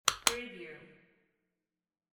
Handbag lock sound effect .wav #8
Description: The sound of a handbag lock snapping open or close (two clicks)
Properties: 48.000 kHz 16-bit Stereo
Keywords: handbag, purse, bag, lock, locking, unlocking, snap, click, open, opening, close, closing, shut, shutting, latch, unlatch
handbag-lock-preview-8.mp3